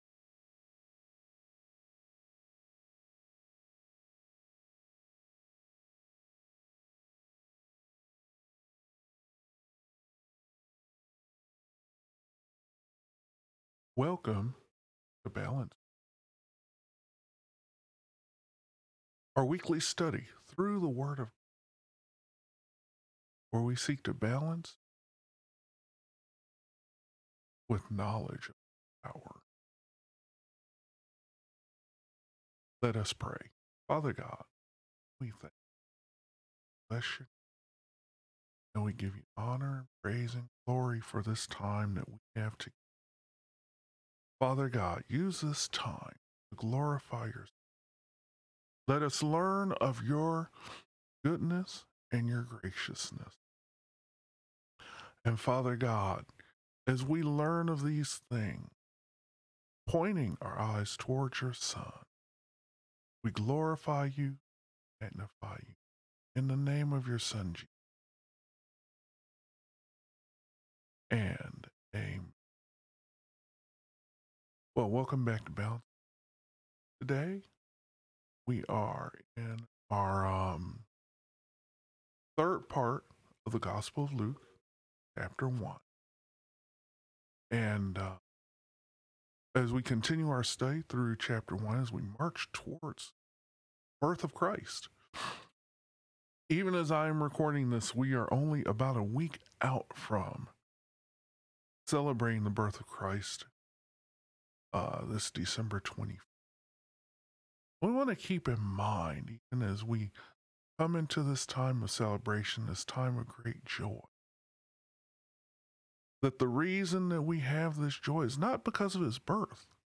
Sermons – Page 3 – Balance Point Christian Community
Service Type: Thursday